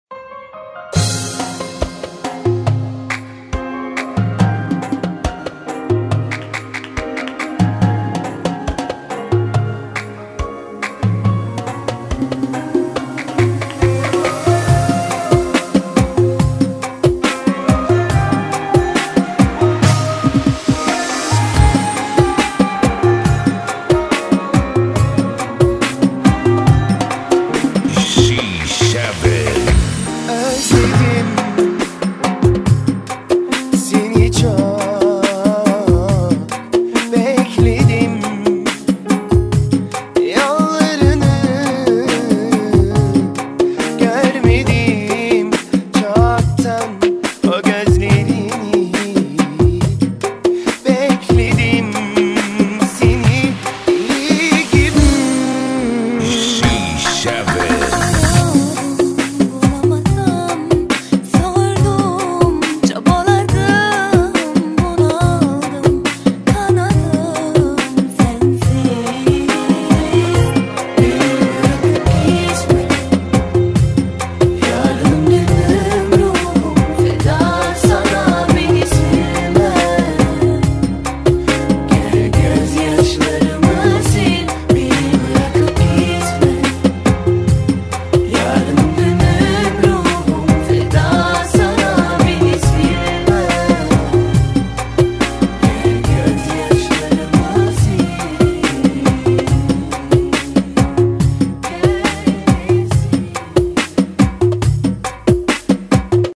BPM 75